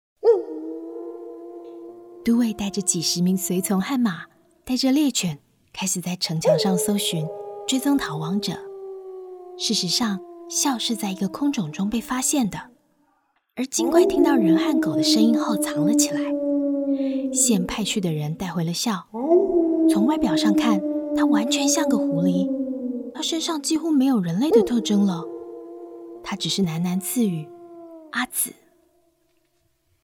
台語配音 國語配音 女性配音員
有聲書 _ 魅力神秘戲劇】狐狸的獨白節錄
✔ 多變聲線，擅長詮釋溫柔療癒、明亮自信、活潑俏皮等廣告調性
✔ 高辨識度中音女聲，適合企業品牌廣告、銀行、食品、家電類產品旁白